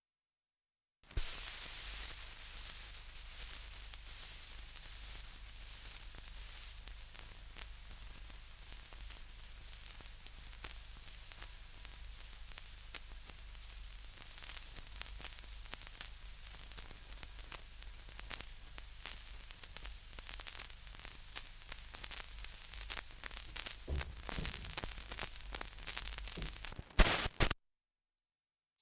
4 - Dialect recording in Newbiggin-by-the-Sea, Northumberland
78 r.p.m., cellulose nitrate on aluminium